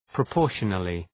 Προφορά
{prə’pɔ:rʃənəlı} (Επίρρημα) ● αναλογώς